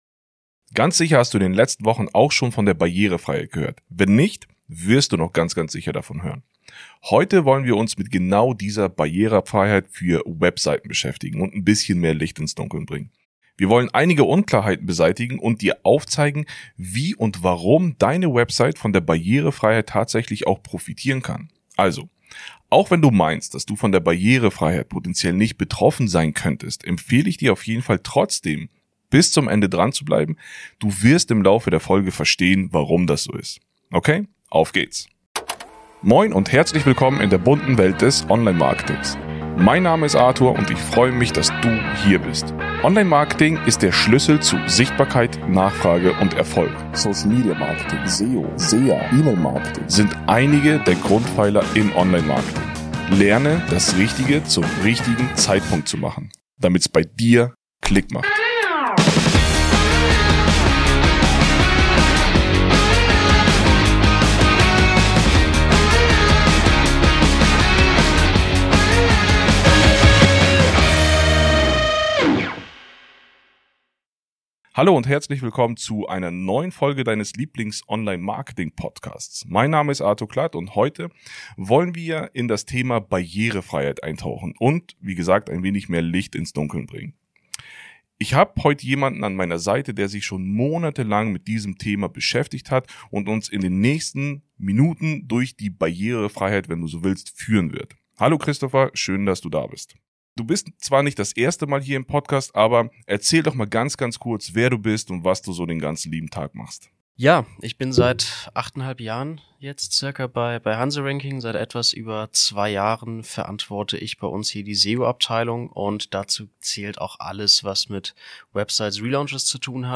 Bleib außerdem dran für kommende Folgen, in der ich dir tiefere Einblicke in spezifische Online Marketing Strategien und Interviews mit weiteren Experten gebe.